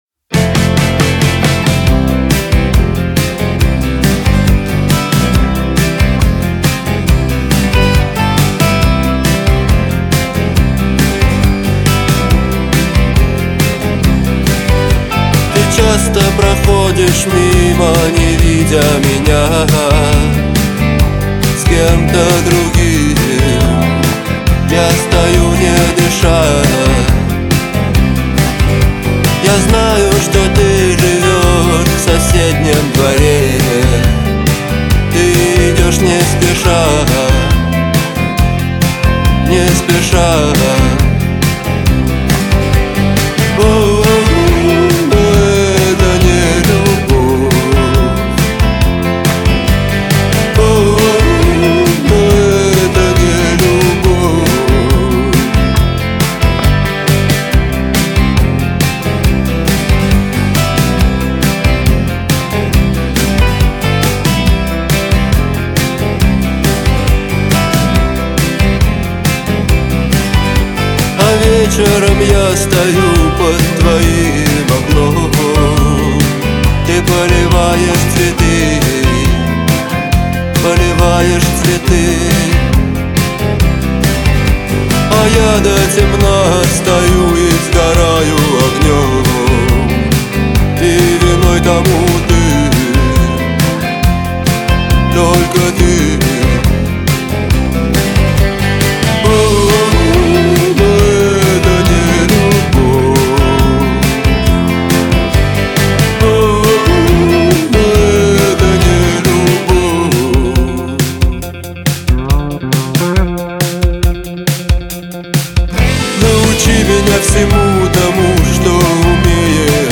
Жанр: Рок